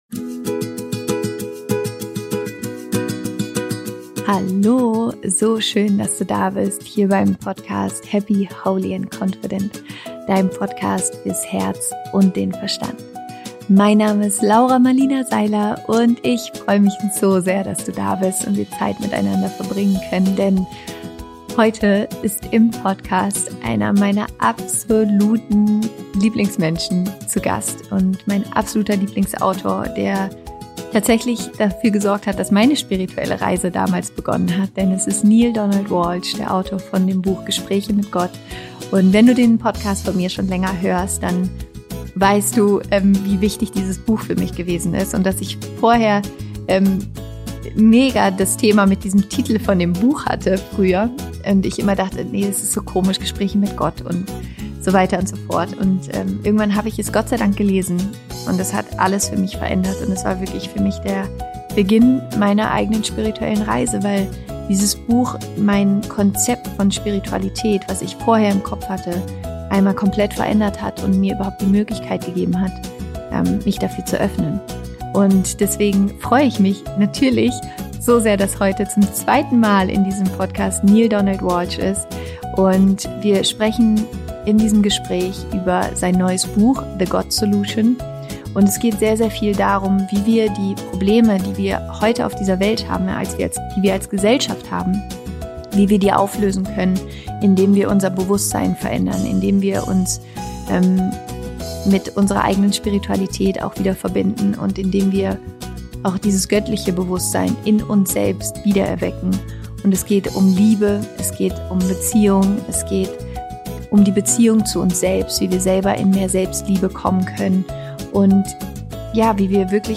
Die Antwort auf alle unsere Probleme - Interview Special mit Neale Donald Walsch
Ich bin unendlich dankbar, heute zum 2. Mal einen meiner absoluten Lieblingsautoren im Podcast zu Gast zu haben: Neale Donald Walsch!